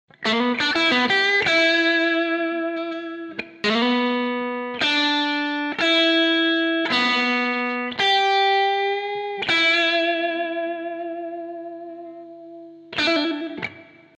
Tutorial Blues Lick ☝🎸 (Fast/Slow)